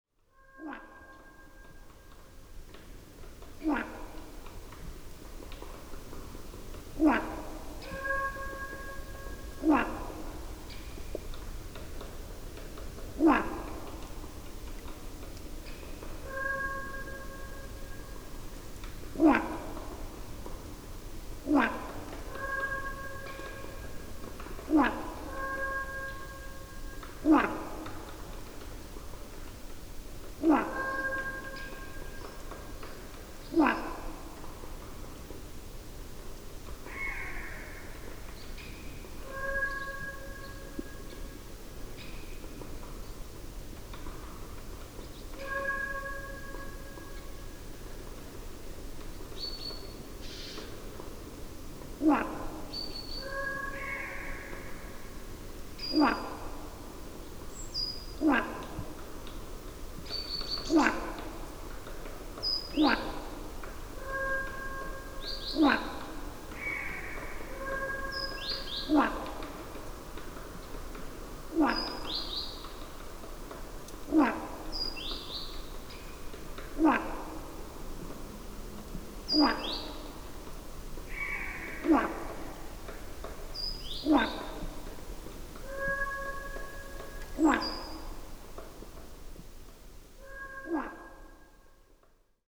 Here some impressions of my trips to the Altmark (Saxony Anhalt) during this winter.
PFR10478, 2-00, 150121, Goldcrest Regulus regulus, 20, foraging on the ground, social calls
Kämeritz, Germany, Telinga parabolic reflector